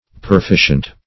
perficient - definition of perficient - synonyms, pronunciation, spelling from Free Dictionary
Search Result for " perficient" : The Collaborative International Dictionary of English v.0.48: Perficient \Per*fi"cient\, a. [L. perficiens, p. pr. of perficere to perform.